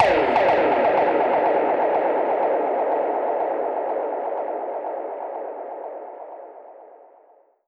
Index of /musicradar/dub-percussion-samples/125bpm
DPFX_PercHit_C_125-03.wav